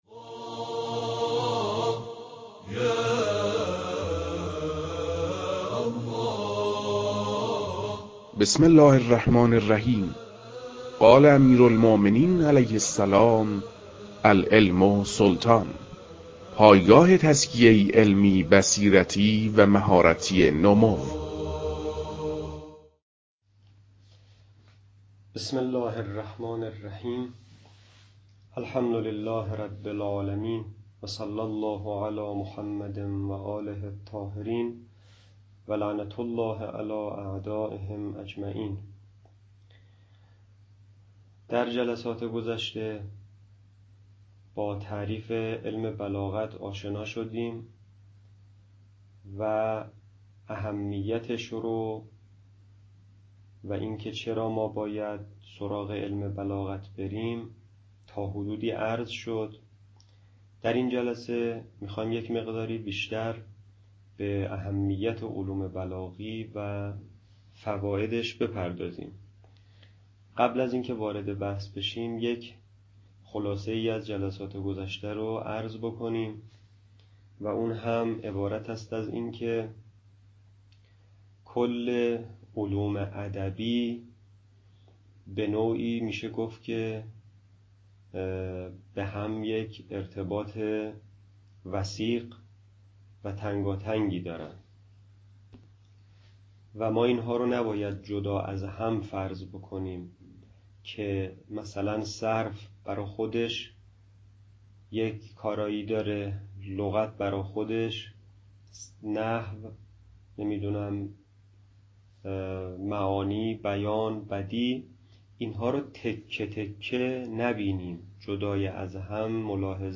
درس اول | اهمیت علم بلاغت